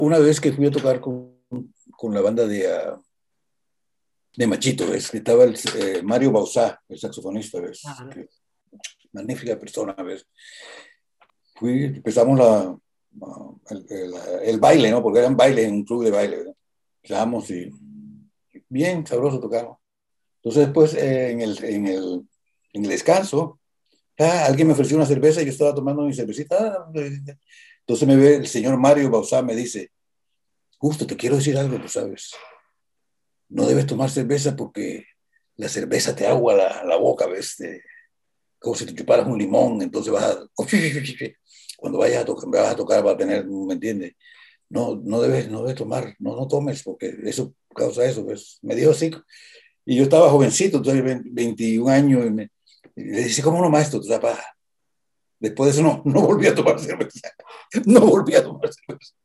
Biografía Justo Almario: Audio 28. Testimonio de Almario de un consejo que recibió de Mario Bauzá